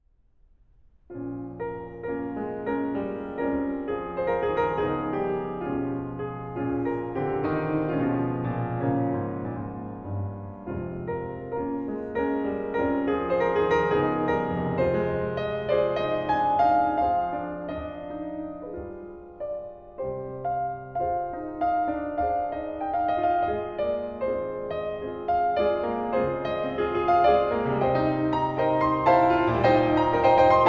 Musique audio